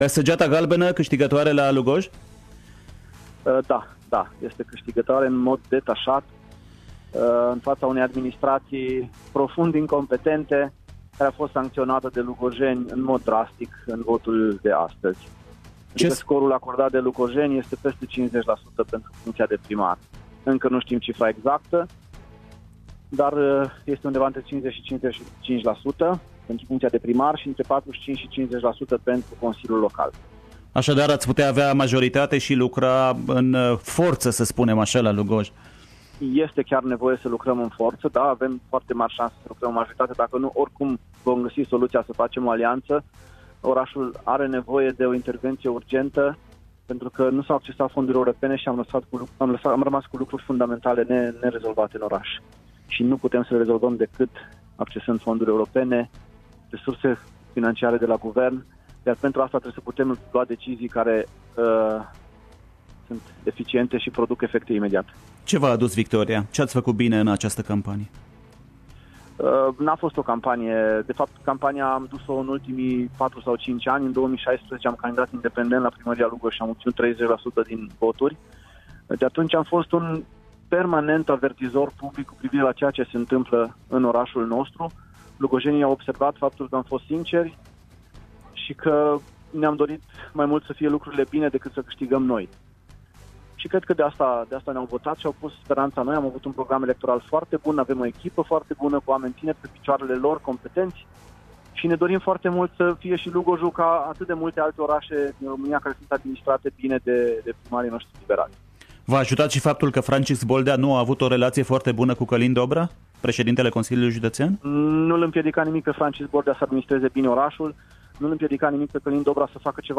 Claudiu Buciu a declarat, la Radio Timişoara, că municipiul are nevoie urgentă de investiţii cu fonduri europene şi guvernamentale.
Ascultați integral ce ne-a spus proaspătul primar în primele minute ale zilei de 28 septembrie 2020: